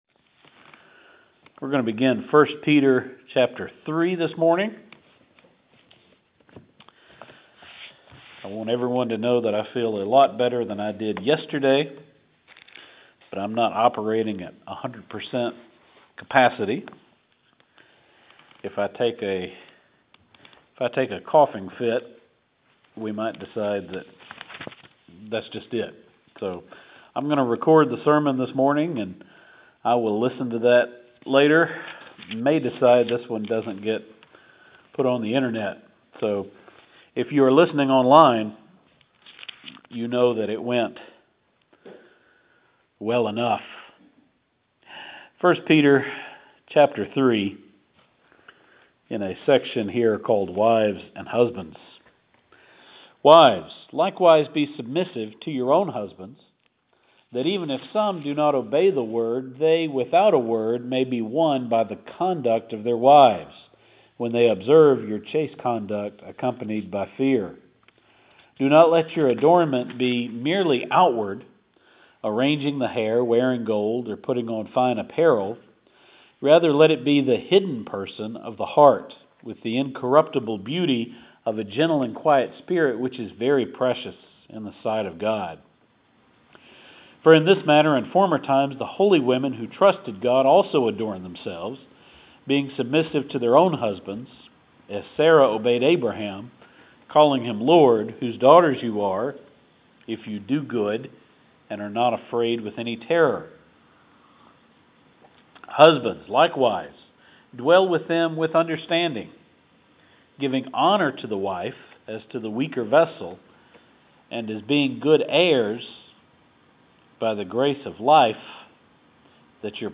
Note: the pastor is not quite well. If it would bother you to hear me cough and apologize then maybe don’t play this one.